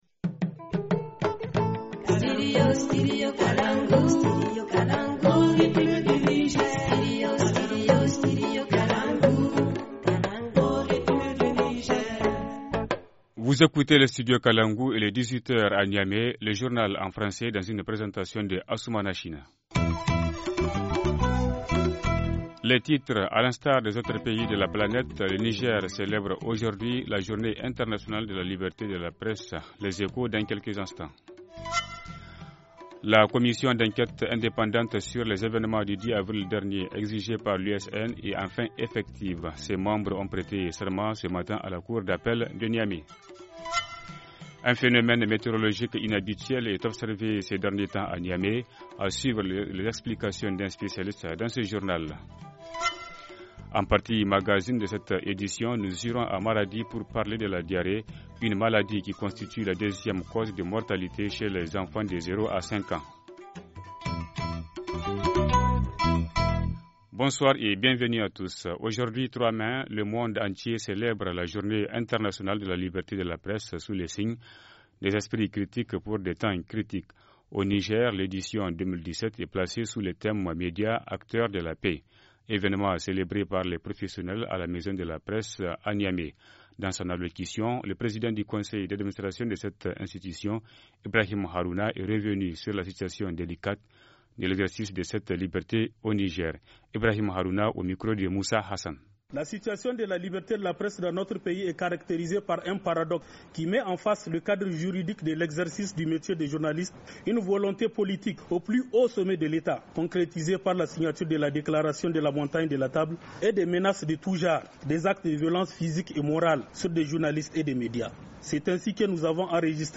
Journal du 3 mai 2017 - Studio Kalangou - Au rythme du Niger